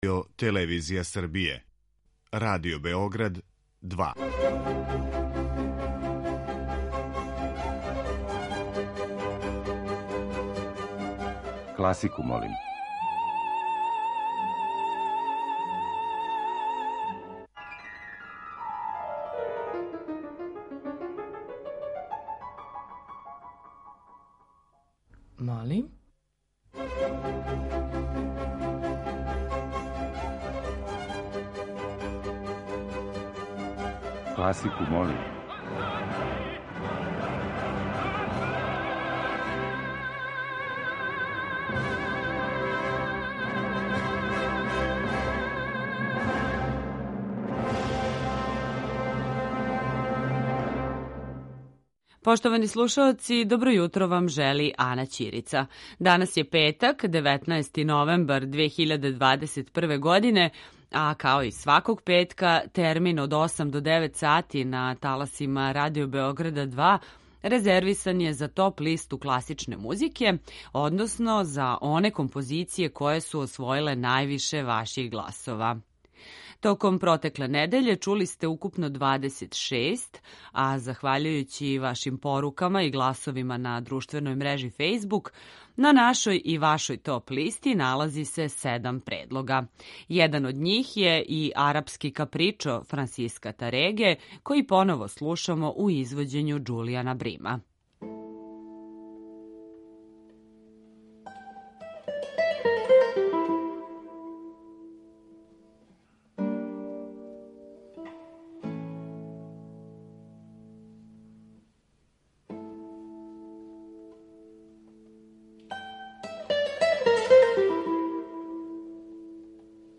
klasika.mp3